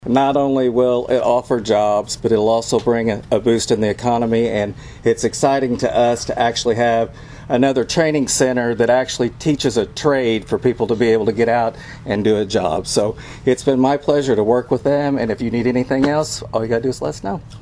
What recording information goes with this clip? Southeastern Illinois personnel as well as community members instrumental in bringing the new center were on hand for the official ground-breaking ceremony Tuesday afternoon.